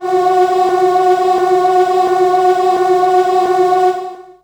55be-syn13-f#3.wav